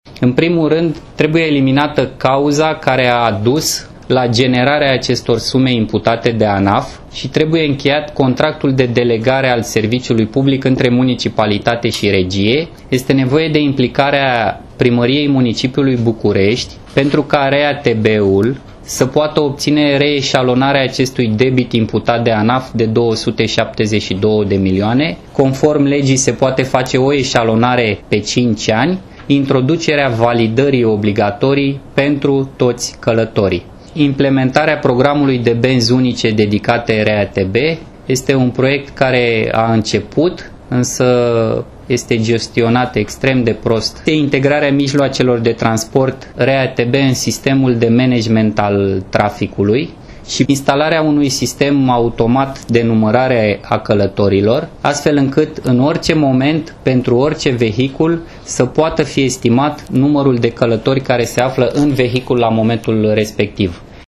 Viceprimarul Capitalei, Cornel Pieptea: